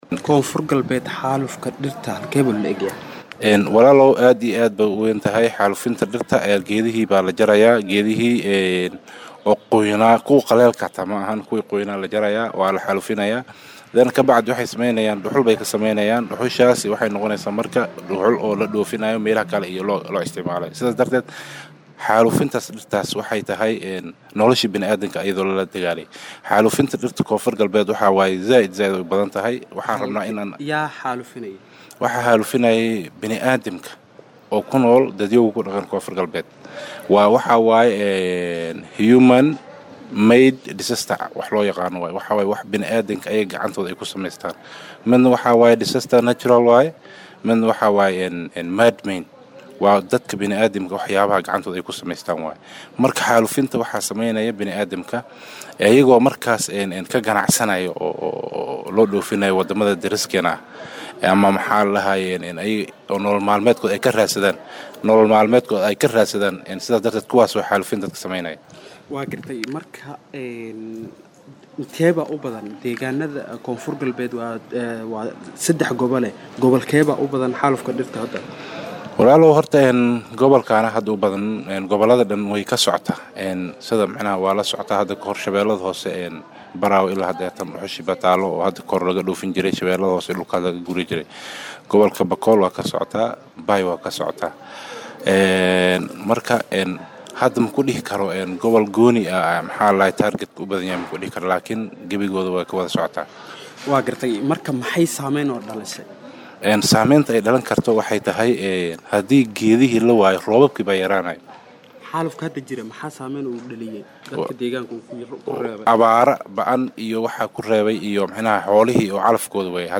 (ERGO) Wasiirka Bay’ada iyo duurjoogta ee Koonfurgalbeed Cali Isaaq Cumar ayaa sheegay in jarista dhirta iyo gubista ay aad uga jirto deegannada u ka arrimo maamulka Koonfur GAlbeed. Wasiirka oo wareysi siiyay idaacadda Ergo ayaa sheegay in dadka jaraya geedaha ay nolal maalmeed ka raadsadaan.